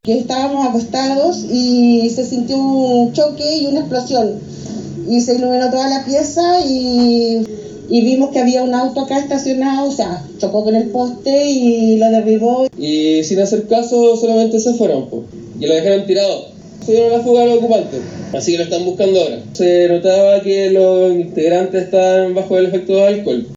Los vecinos relataron cómo fue el momento del accidente.
cu-vecinos-poste-caido.mp3